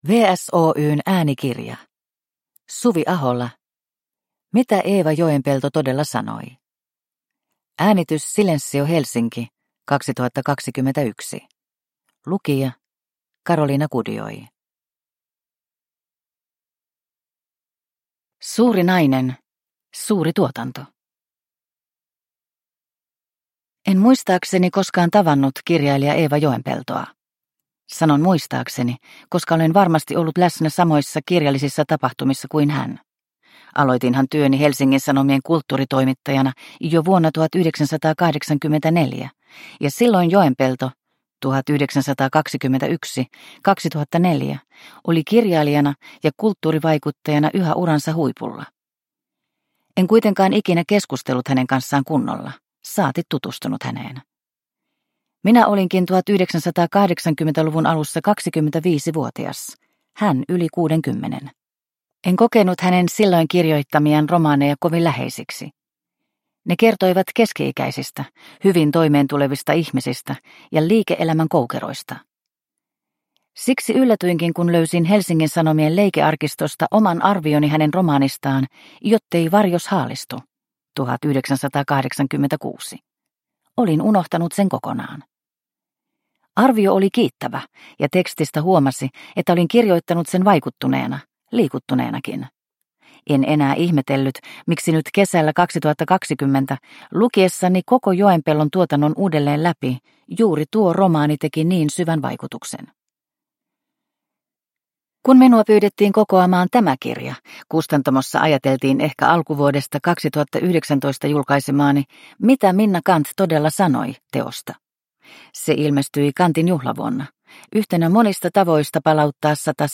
Mitä Eeva Joenpelto todella sanoi? – Ljudbok – Laddas ner